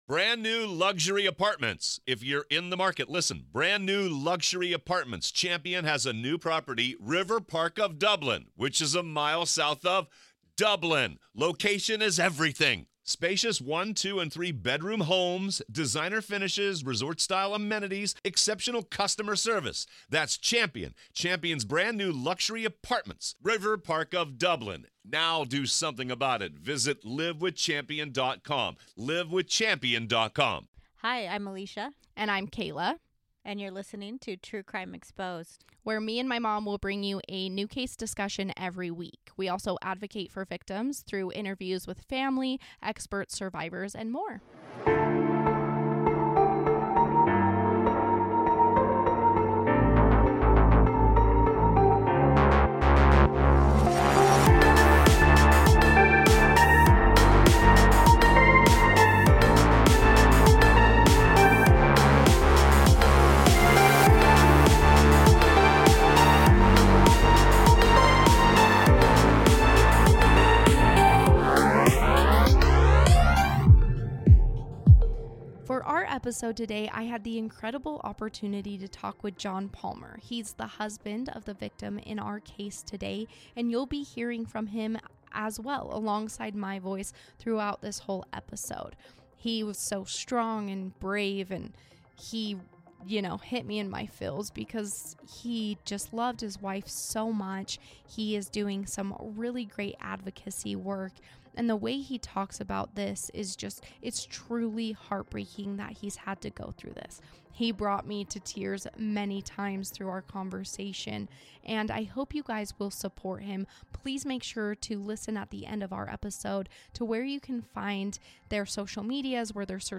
Interview Included